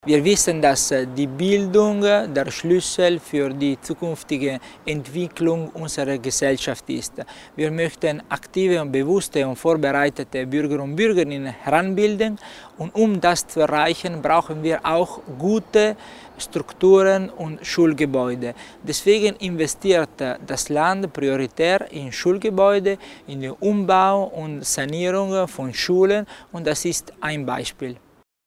Landesrat Tommasini zur Wichtigkeit des Ausbaus der Kunter-Schule